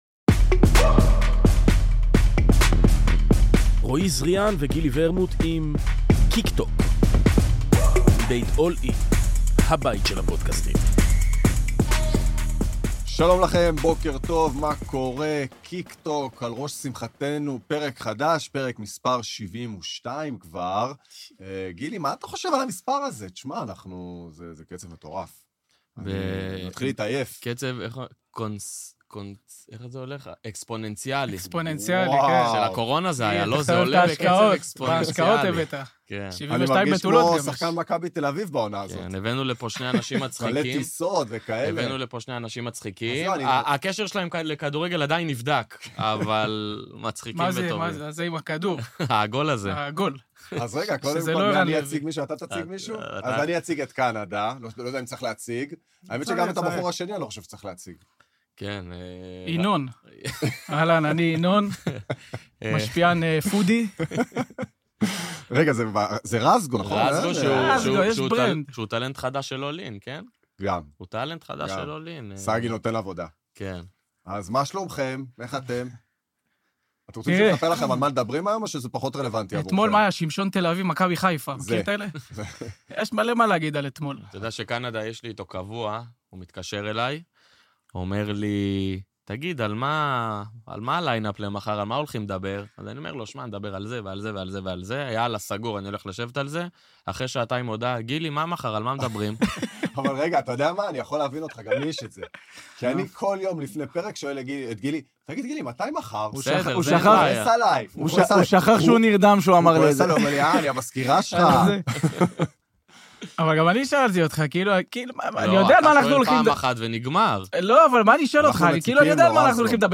אורחים באולפן